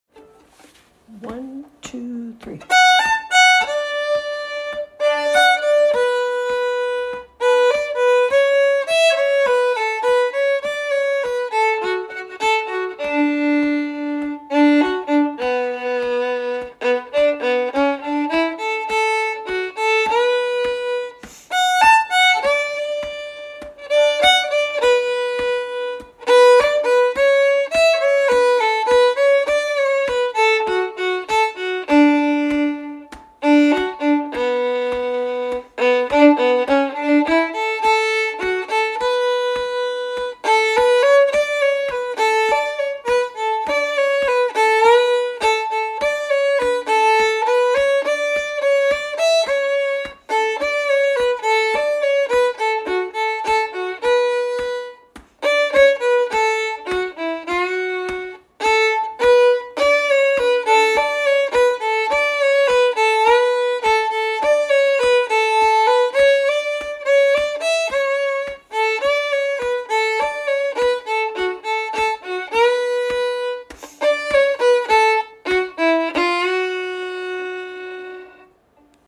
Key: Bm
Form: Reel
Genre/Style: Nordic…what?